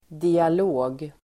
Uttal: [dial'å:g]